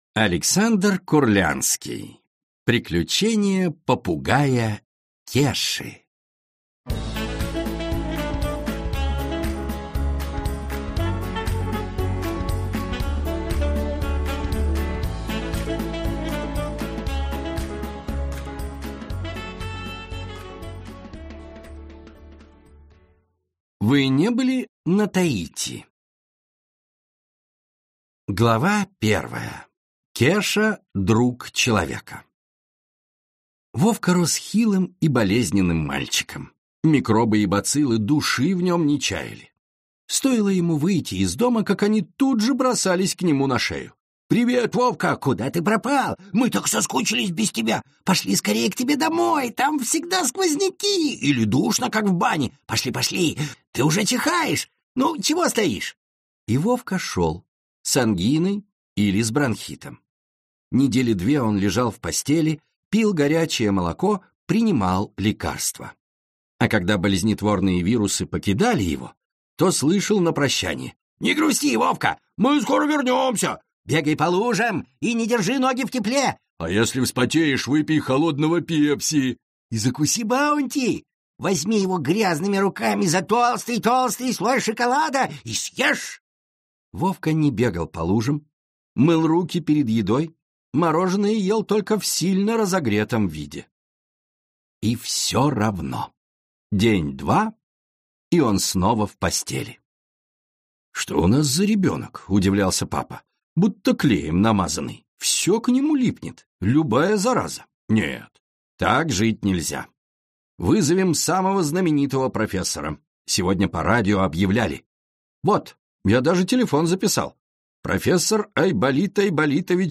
Аудиокнига Приключения попугая Кеши | Библиотека аудиокниг